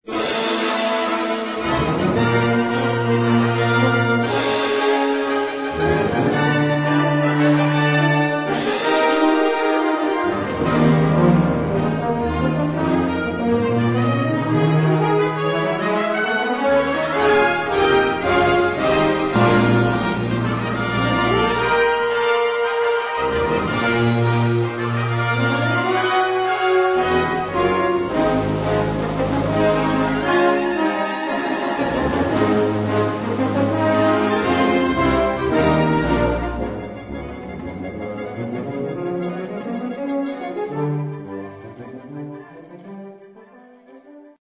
Categorie Harmonie/Fanfare/Brass-orkest
Subcategorie Ouverture (originele compositie)
Bezetting Ha (harmonieorkest)